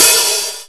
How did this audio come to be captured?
Note: The WAV files were converted directly from the binary EPROM files, and do not have any filtering or envelope effects which you would hear when listening to the audio output of the DMX.